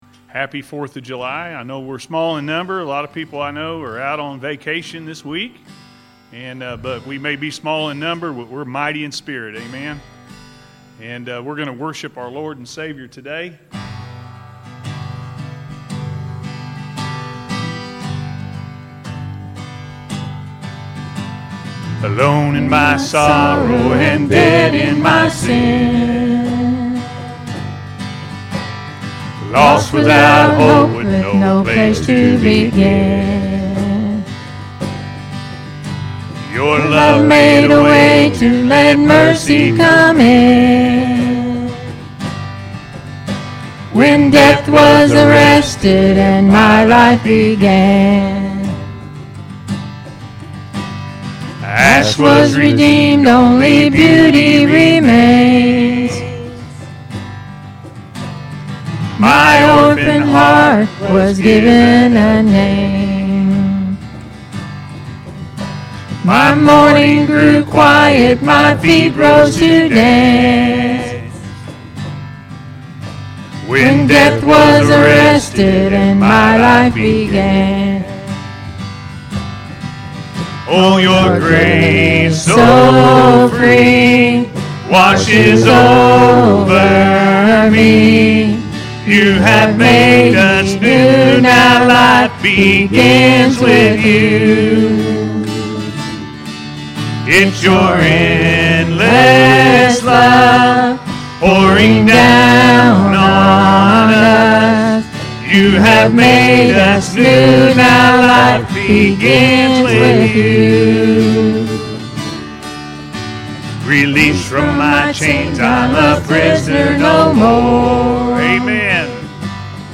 Only In America – A.M. Service – Anna First Church of the Nazarene